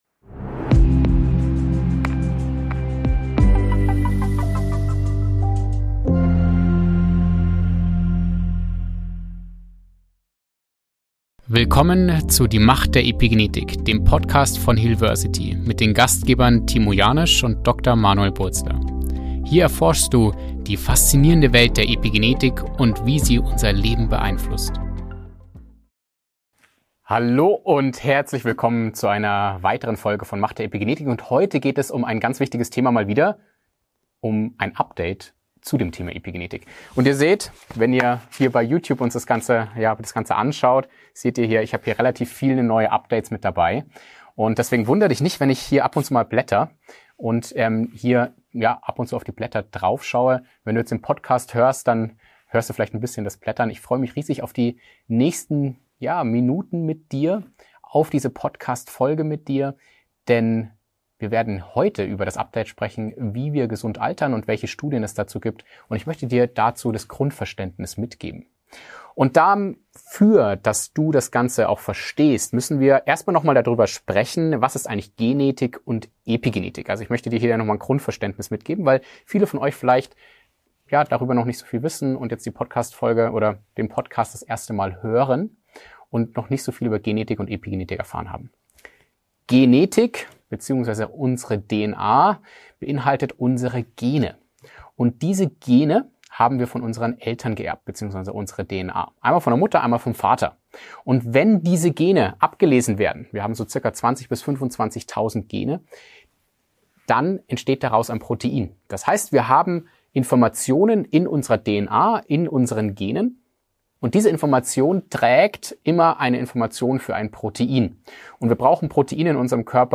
In dieser spannenden Solo-Episode